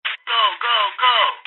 radio_com_go.mp3